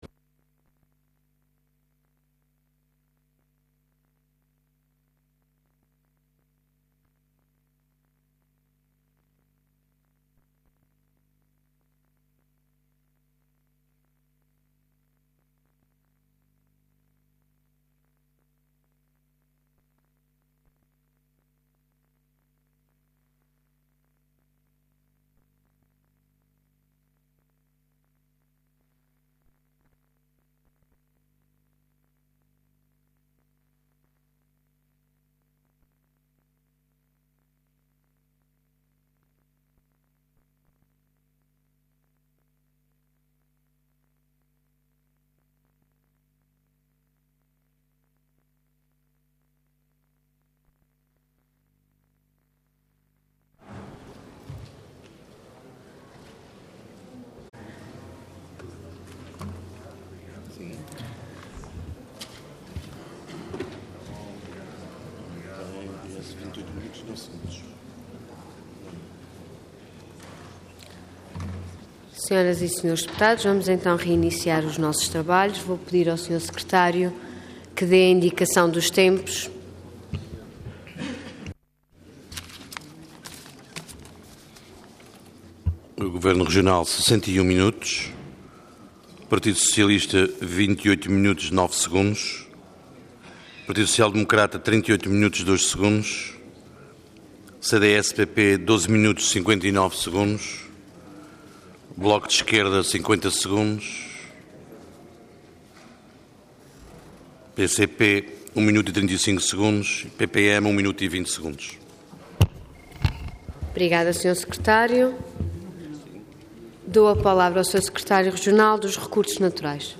Detalhe de vídeo 21 de março de 2013 Download áudio Download vídeo Diário da Sessão Processo X Legislatura Plano e Orçamento para 2013 Intervenção Intervenção de Tribuna Orador Luís Neto de Viveiros Cargo Secretário Regional dos Recursos Naturais Entidade Governo